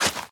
Minecraft Version Minecraft Version snapshot Latest Release | Latest Snapshot snapshot / assets / minecraft / sounds / item / shovel / flatten1.ogg Compare With Compare With Latest Release | Latest Snapshot
flatten1.ogg